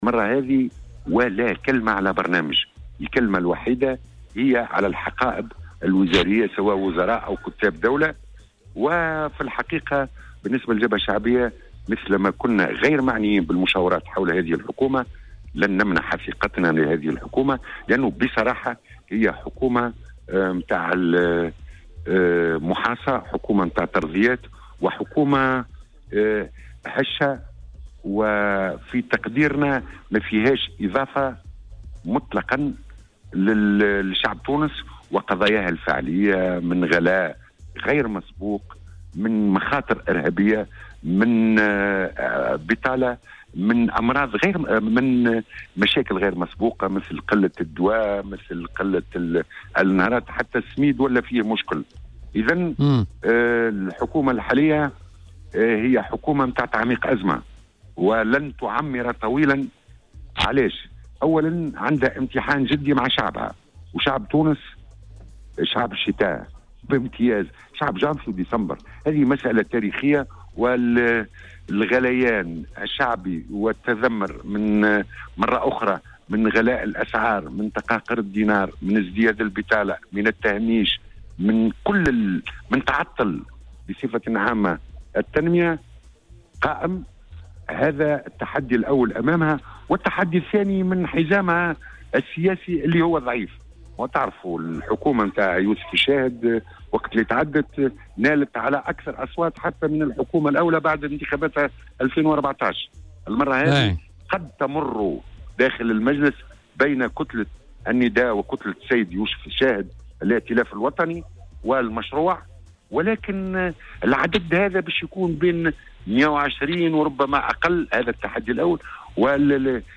وقال في مداخلة في برنامج "بوليتيكا" على "الجوهرة اف أم" أنه متأكد من هذه المعلومة وليست مجرد تسريبيات، منتقدا الطريقة التي تمّ من خلالها إعلان التحوير الوزاري.